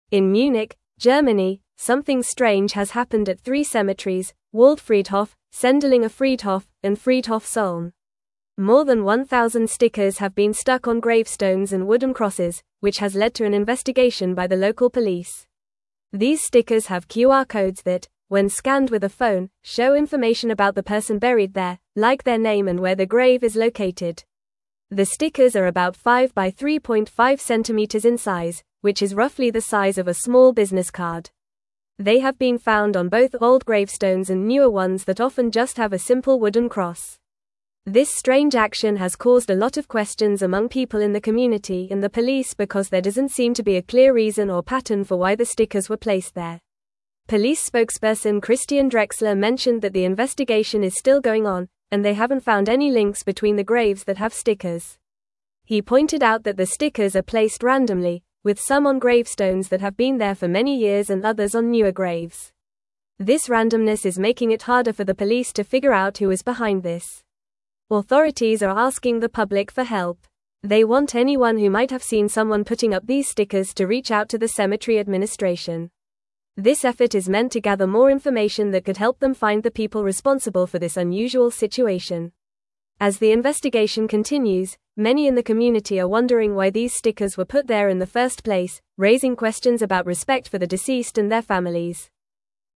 Fast
English-Newsroom-Upper-Intermediate-FAST-Reading-QR-Code-Stickers-Appear-on-Gravestones-in-Munich.mp3